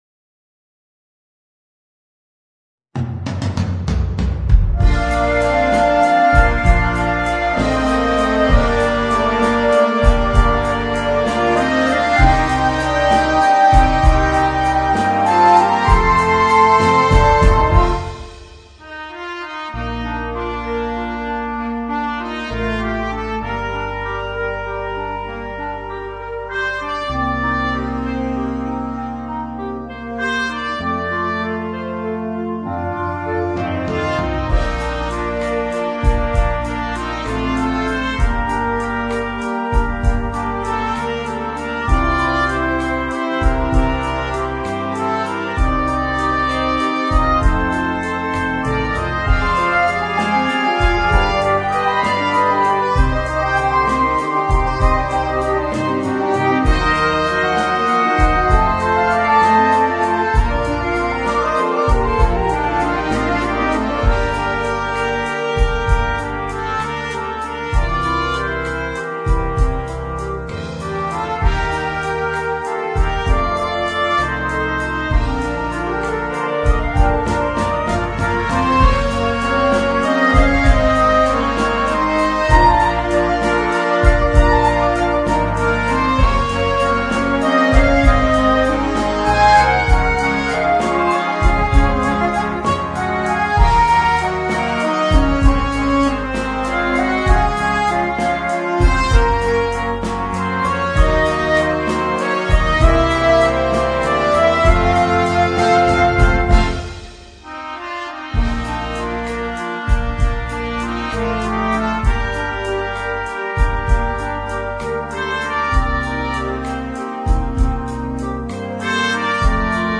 per 2 trombe e banda